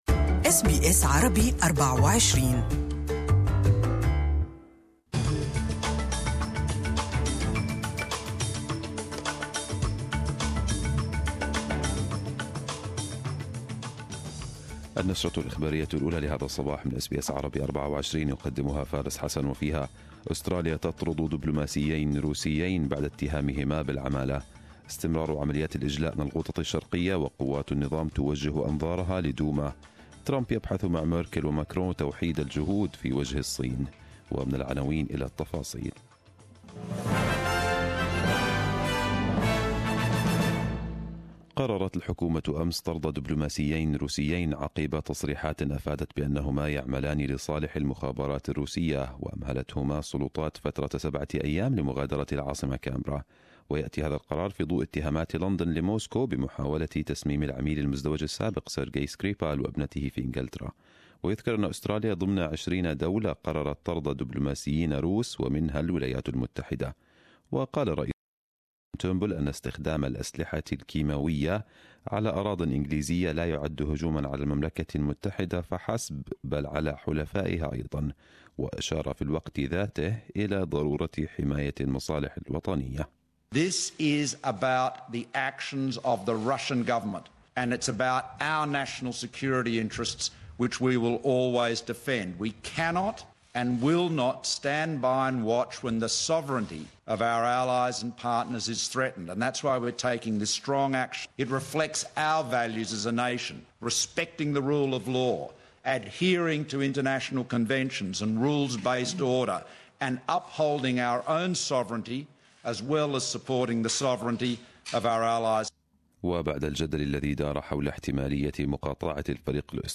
Arabic News Bulletin 28/03/2018